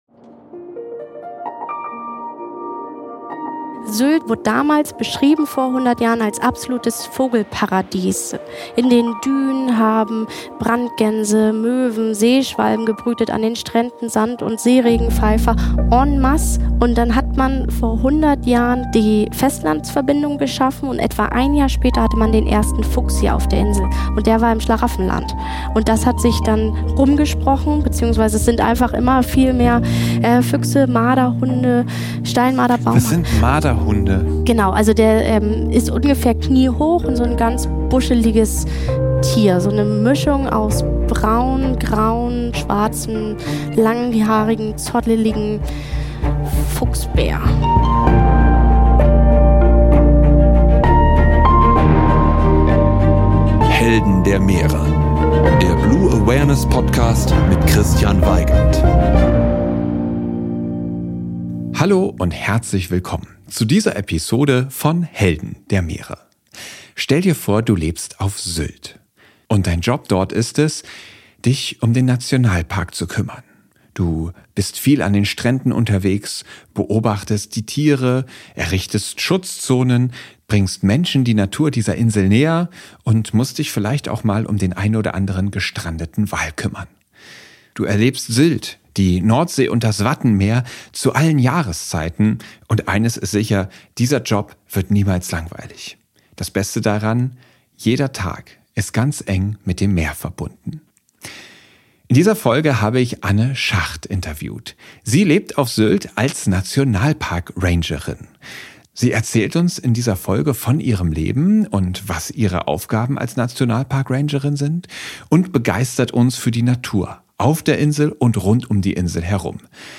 Das Gespräch haben wir im Erlebniszentrum Naturgewalten aufgenommen.